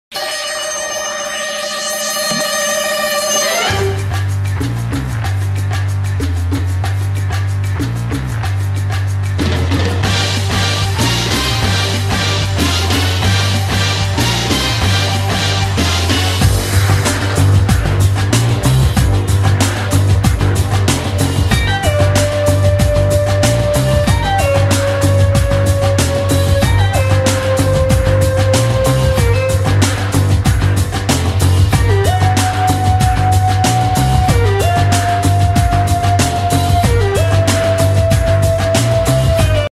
Kategorien Filmmusik